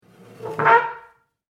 Field Recording #9
Sound heard: chair scraping against the floor
Chair.mp3